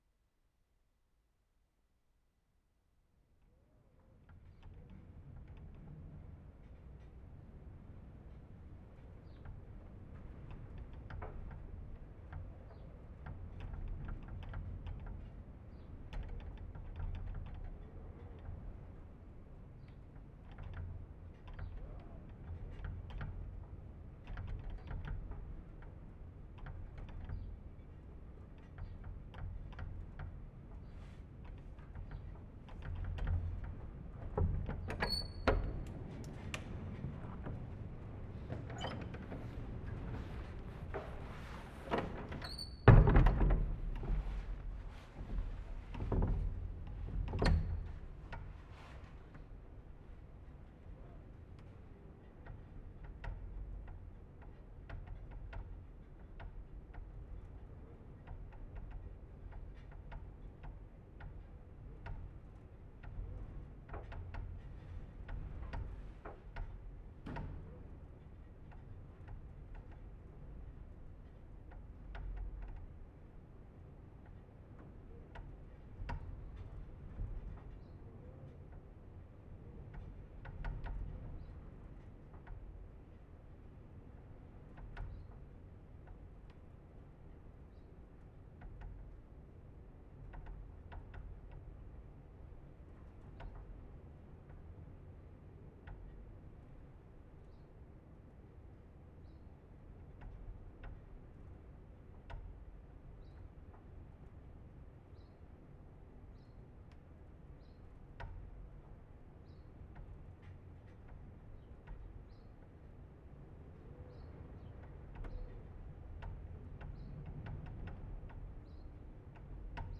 Wensleydale, Yorkshire May 7/75
WIND RATTLING DOOR TO ST. OSWALD'S CHURCH
mark * door squeaks open and bangs shut (very loud) [0:21 & 0:31]
Attempt to record the pattern made by the loosely-fitting door to the church as it rattled in the wind.